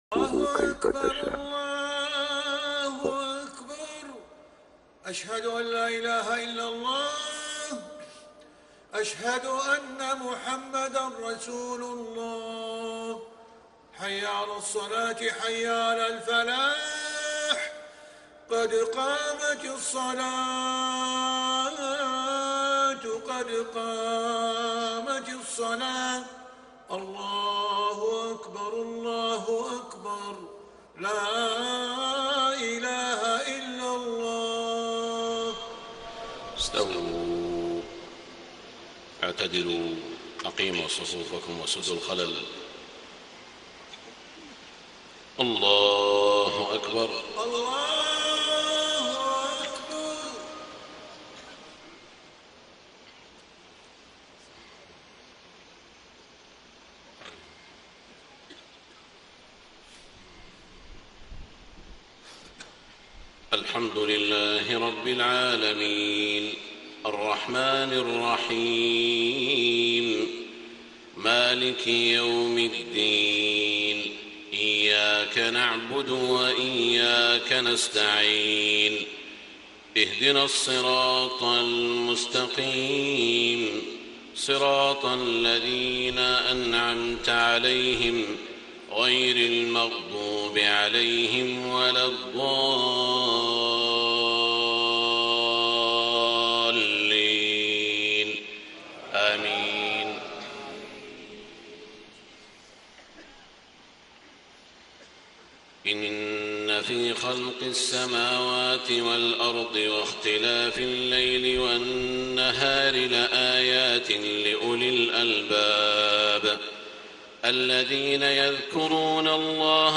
صلاة العشاء 9 - 1 - 1435هـ آخر سورة آل عمران > 1435 🕋 > الفروض - تلاوات الحرمين